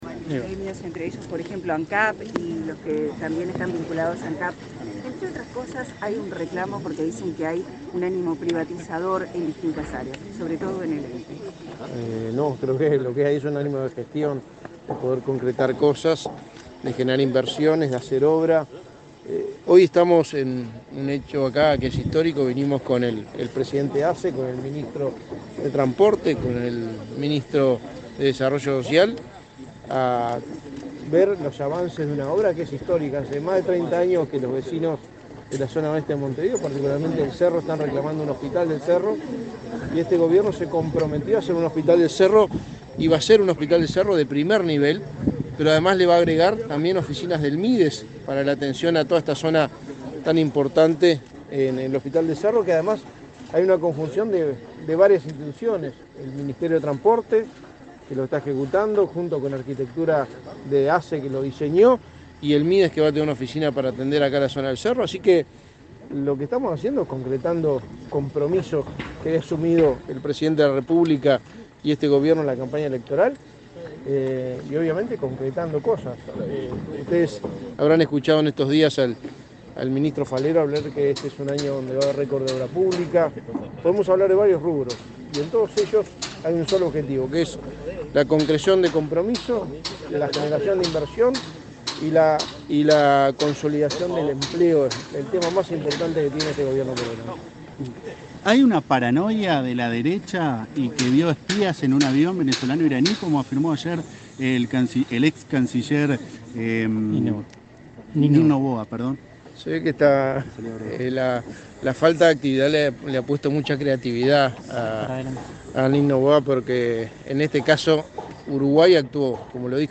Declaraciones a la prensa del secretario de Presidencia, Álvaro Delgado
Este martes 21, el secretario de Presidencia, Álvaro Delgado; los ministros de Desarrollo Social, Martín Lema, y de Transporte y Obras Públicas, José Luis Falero, y el presidente de la Administración de los Servicios de Salud del Estado (ASSE), Leonardo Cipriani, visitaron las obras del hospital del Cerro, en Montevideo. Luego, Delgado dialogó con la prensa.